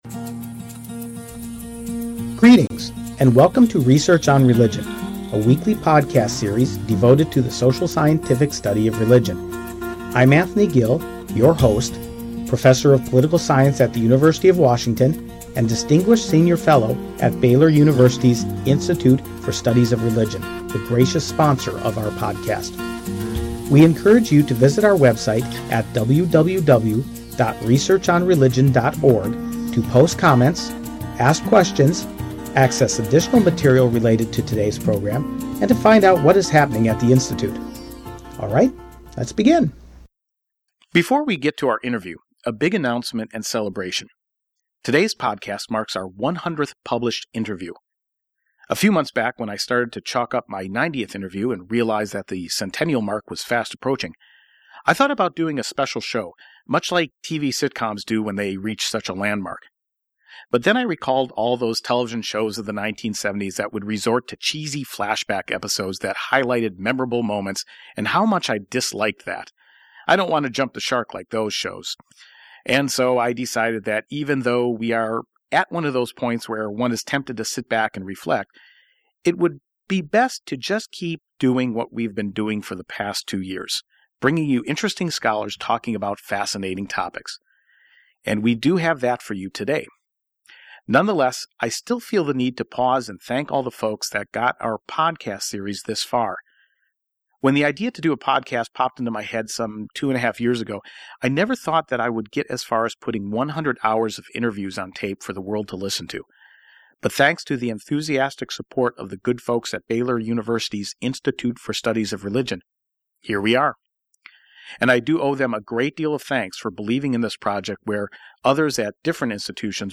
Our conversation begins with a general survey of the religious landscape in Cuba since the 1959 communist revolution.